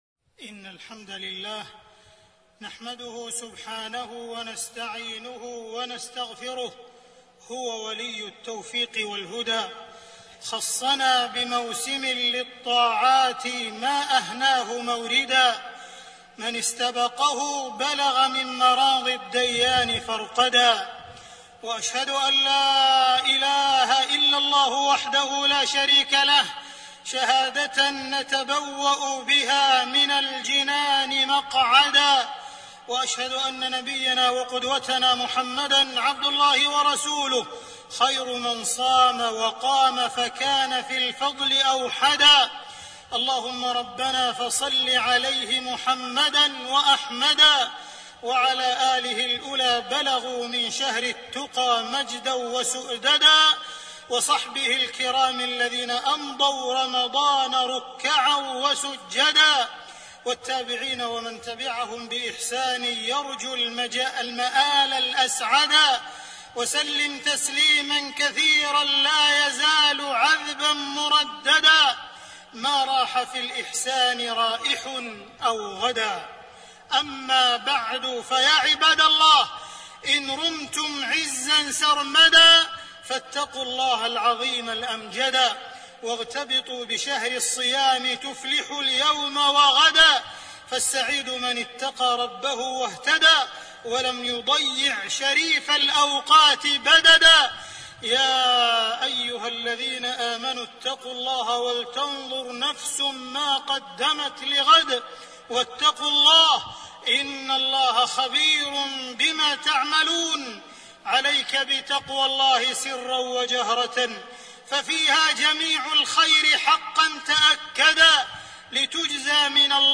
تاريخ النشر ٢٨ شعبان ١٤٣٢ هـ المكان: المسجد الحرام الشيخ: معالي الشيخ أ.د. عبدالرحمن بن عبدالعزيز السديس معالي الشيخ أ.د. عبدالرحمن بن عبدالعزيز السديس رمضان موسم الخيرات والبركات